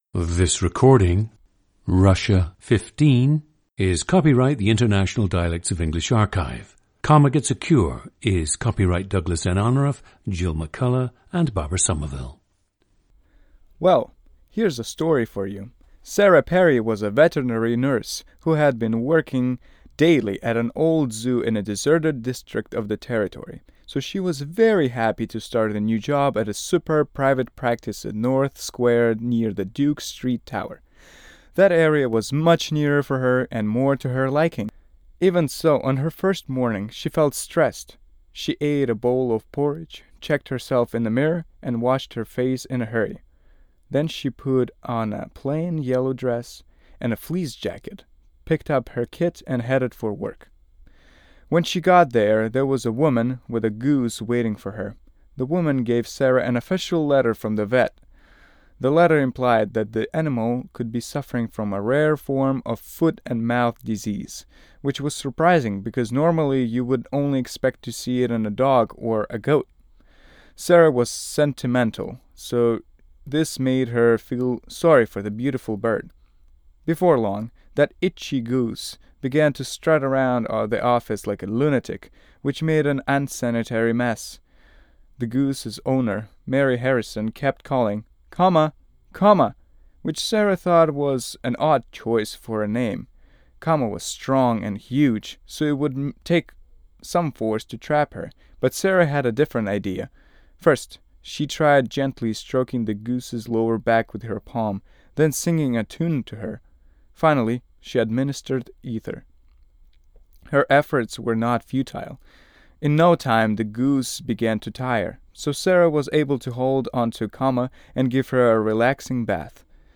GENDER: male
This recording is of a native speaker of Russian who has achieved strong fluency in English.
The sample invites the listener to note elements of the Russian accent which can linger, even with a high level of fluency and training in English. These elements include the focus/placement of sound near the rear of the oral cavity, the relative lack of unstressed syllables, which subtly affects the overall cadence, and the use of a slightly velarized “darker” /l/ in initial position of certain words.
The recordings average four minutes in length and feature both the reading of one of two standard passages, and some unscripted speech.